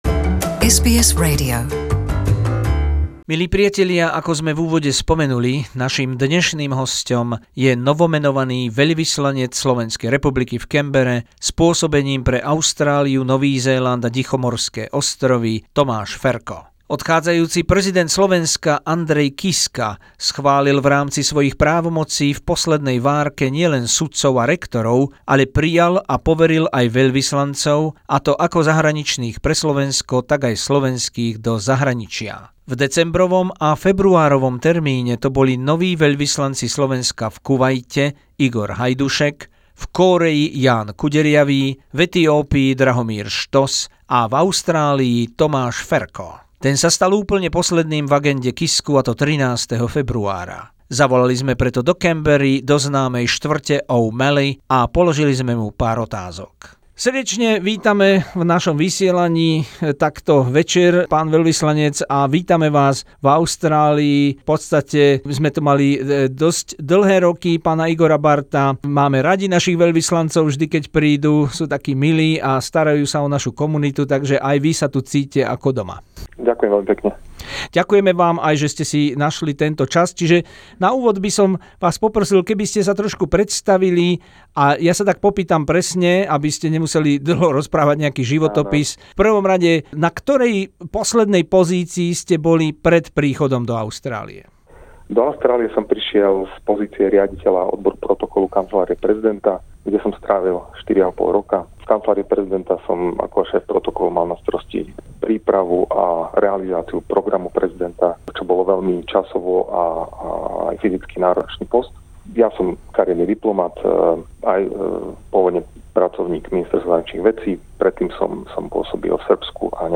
Výhradný rozhovor SBS s Tomášom Ferkom, novým veľvyslancom SR v Canberre